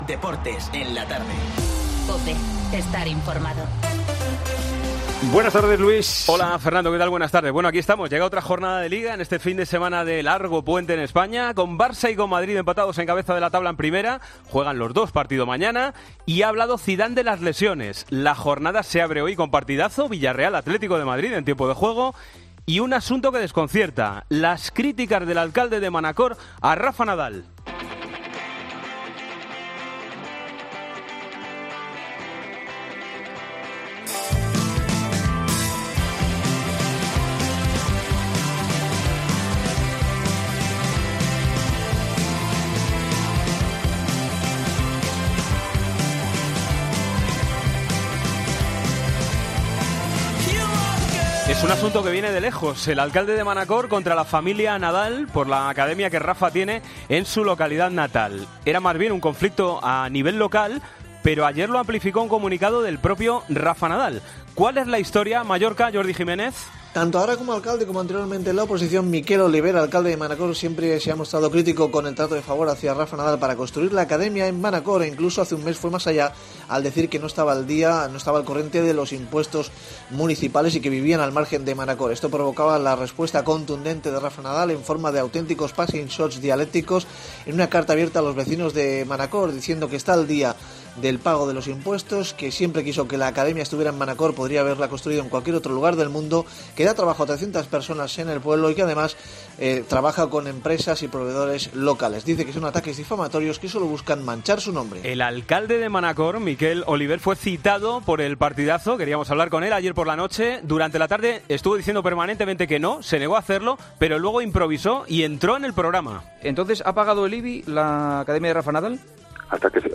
Entrevista a Míchel, técnico del Huesca.